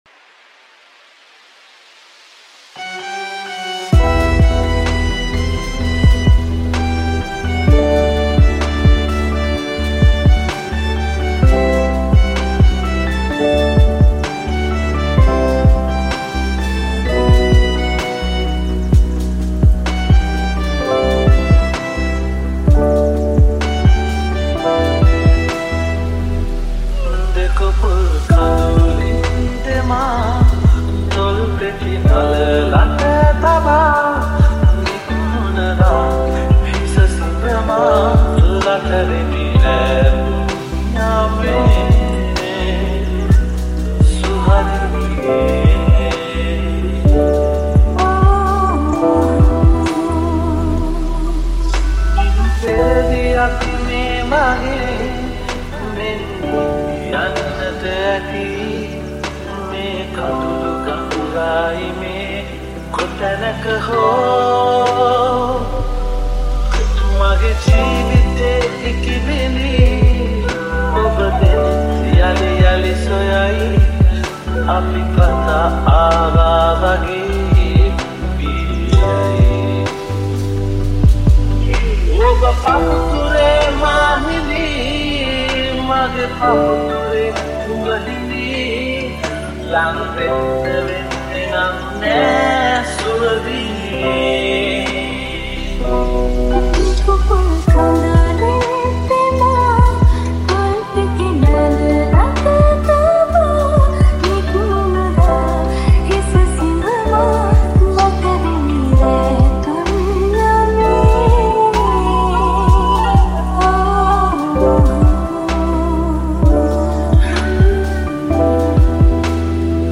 Genre - Electronic